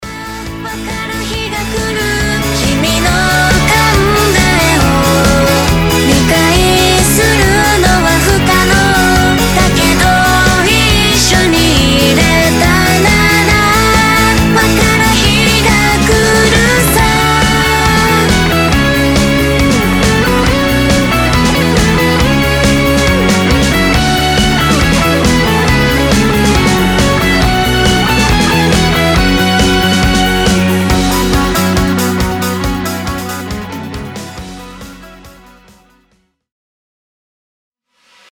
J-POP Rock